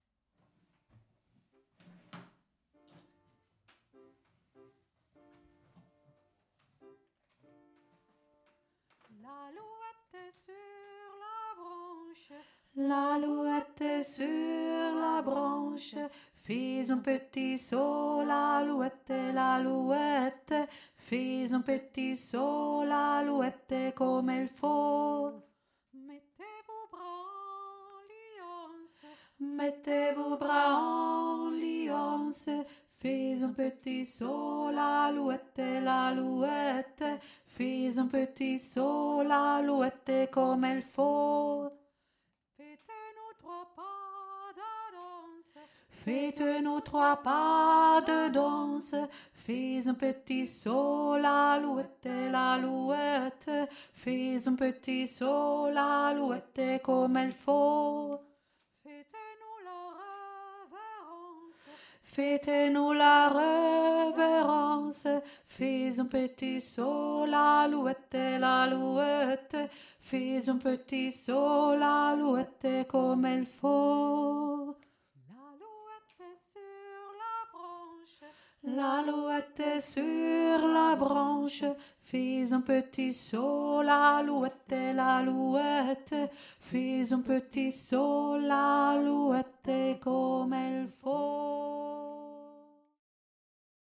mittlere Stimme
l-aluette-mittlere-stimme.mp3